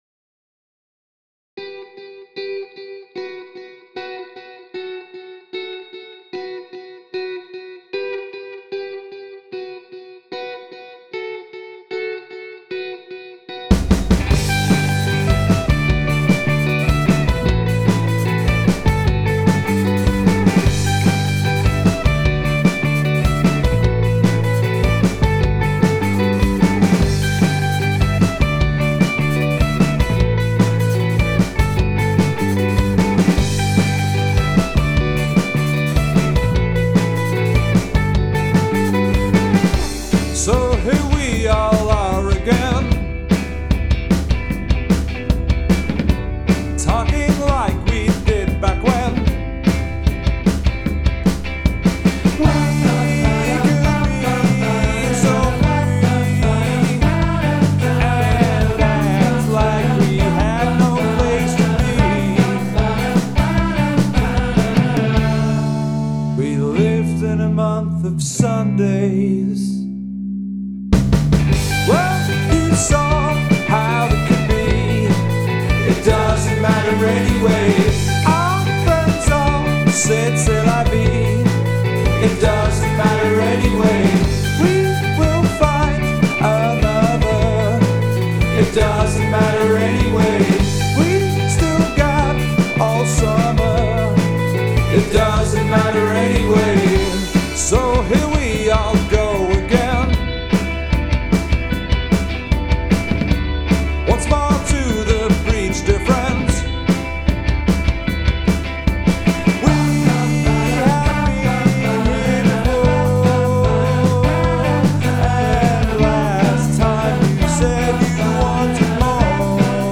Lead Vocals/Rhythm Guitar
Lead Guitar/Backing Vocals
Bass/Backing Vocals
Drums/Percussion